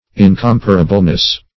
-- In*com"pa*ra*ble*ness, n. -- In*com"pa*ra*bly, adv.